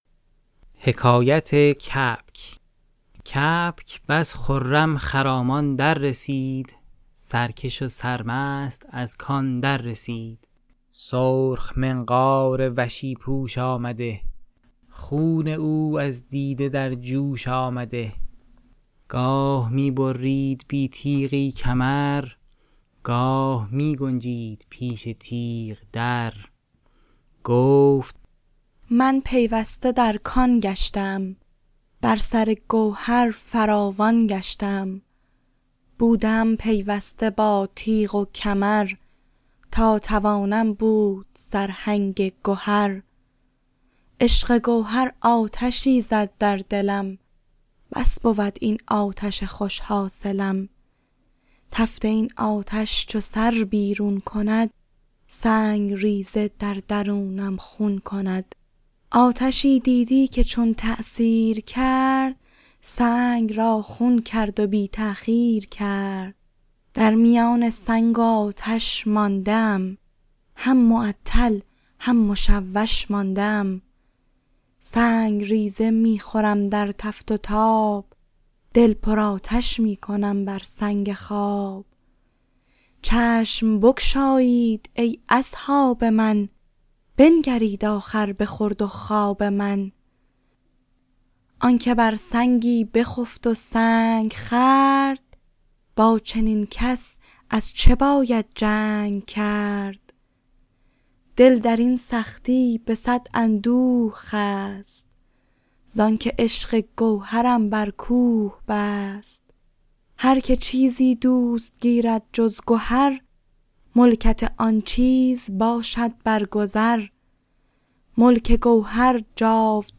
عطار منطق‌الطیر » داستان کبک داستان کبک به خوانش گروه چامه‌خوان